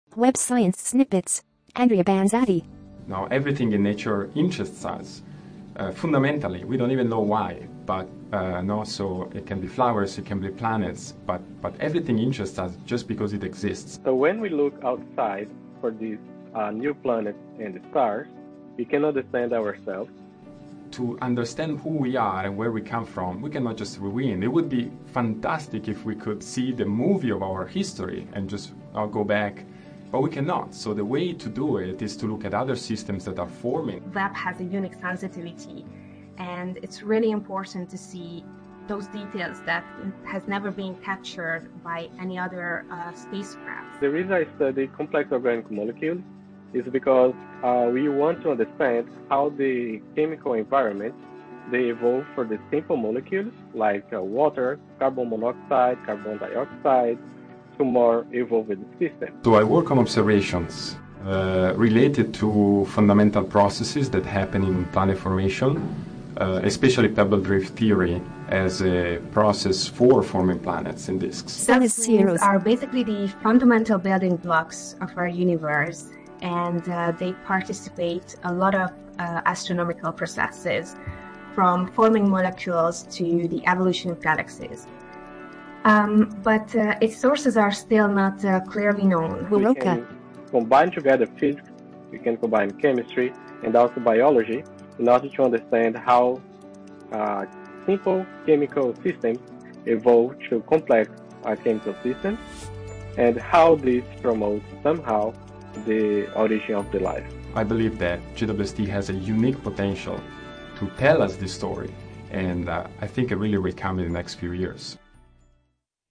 Music: “Appalachia” by Acreage, courtesy of Soundstripe.
Audio Description.mp3